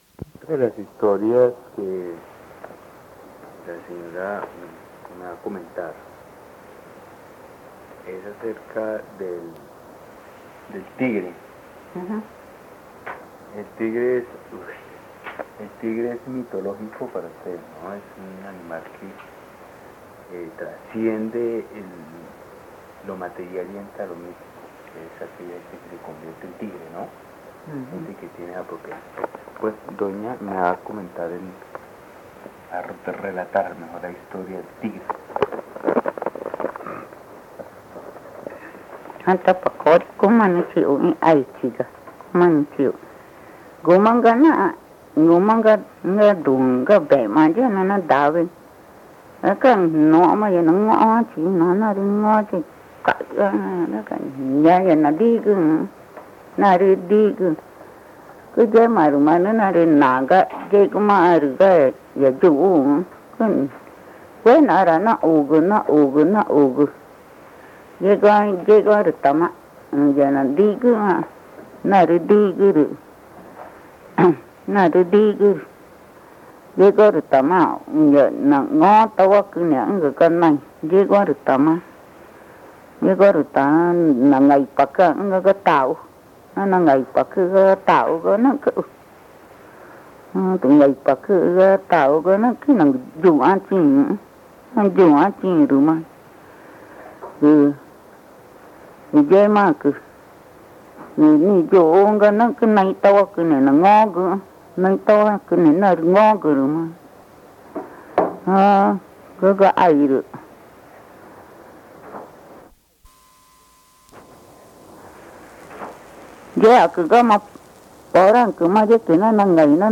Río Amazonas (Colombia), problamente Pozo Redondo
introduce el relato en Español
narra en Magütá la historia de un tigre que estaba buceando. Dentro del agua había un temblón, que le daba corrientazos y lo hacía revolcarse. Finalmente, a la tercera vez, el temblón logró matar al tigre.